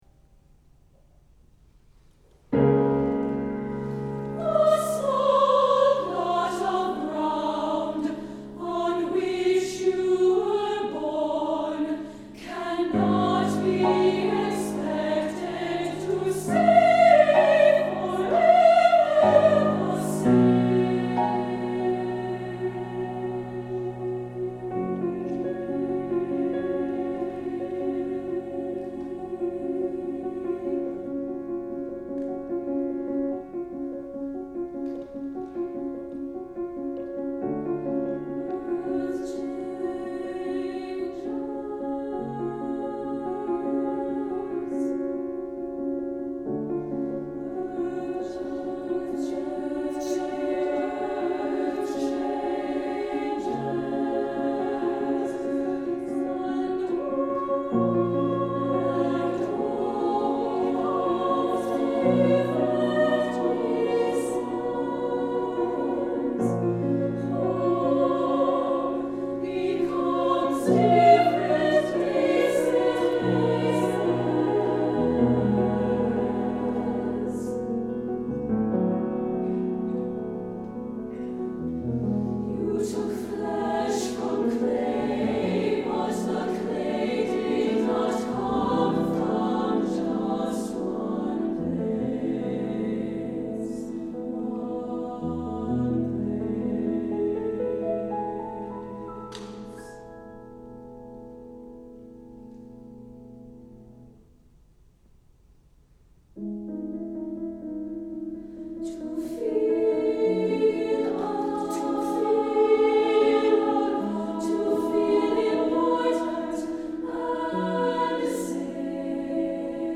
SSAA, piano